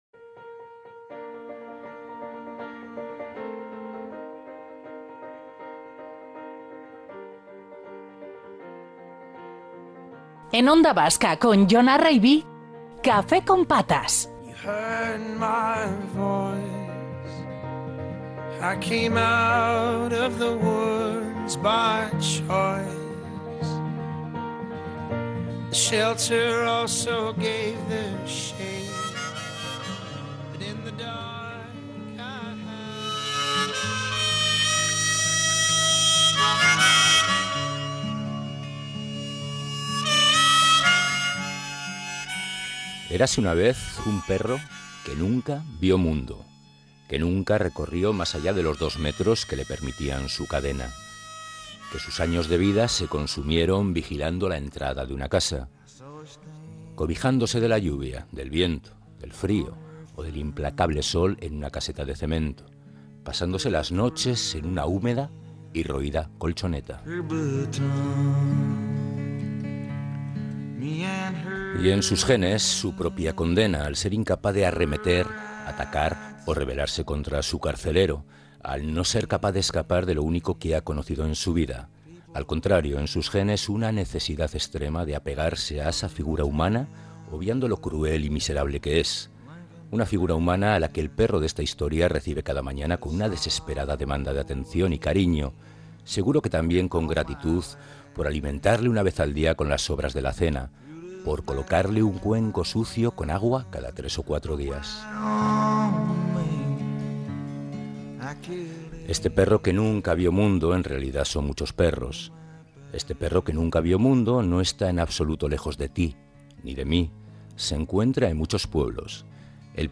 Os damos la bienvenida a un nuevo episodio de Café con Patas, un programa de radio en el que intentamos conocer más a los animales, para quererlos mucho mejor.